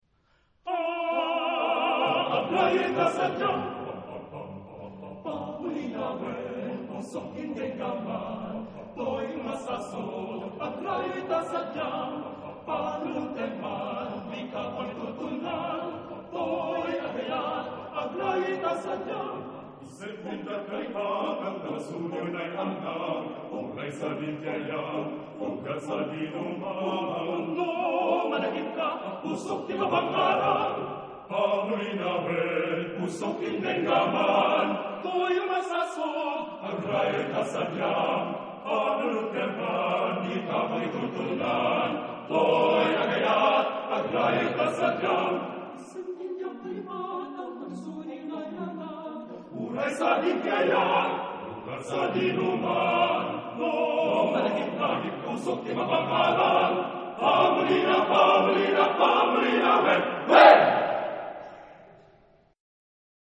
Genre-Style-Form: Secular
Mood of the piece: forceful
Type of Choir: TTBarB  (4 men voices )
Tonality: various